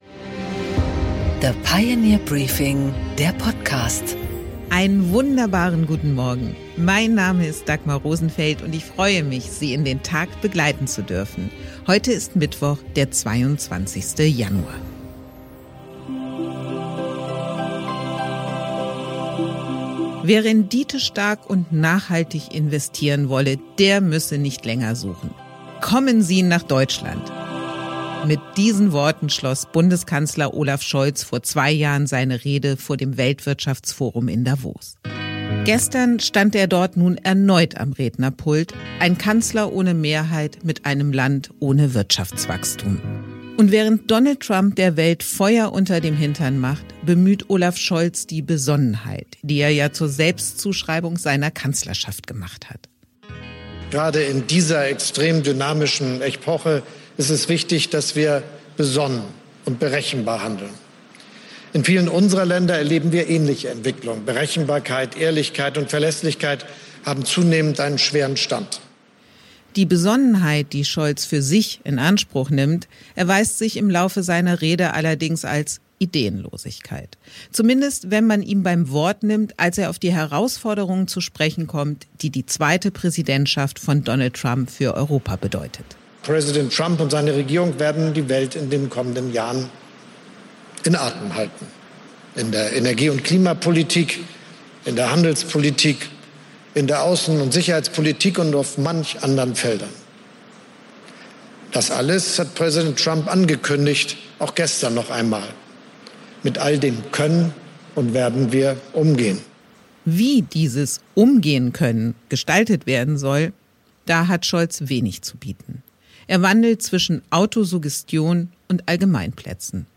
Interview
Die Rede von Bundeskanzler Olaf Scholz beim Weltwirtschaftsforum in Davos.